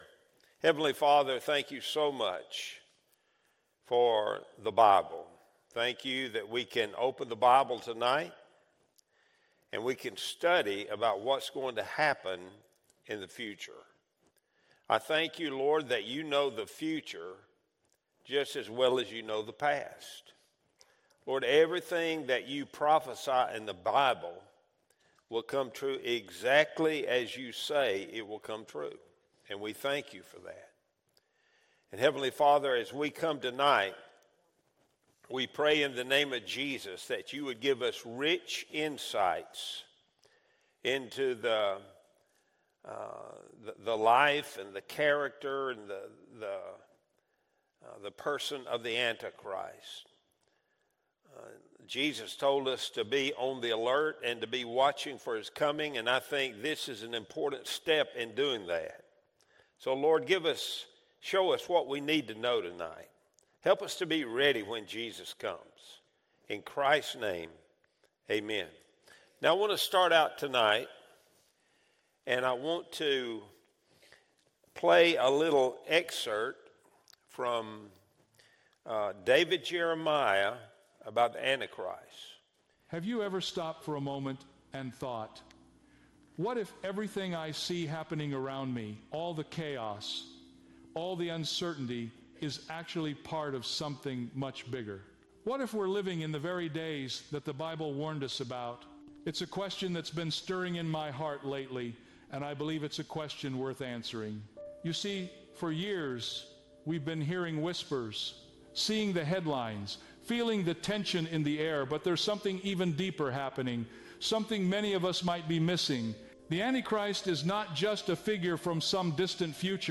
Wednesday Bible Study Series | February 11, 2026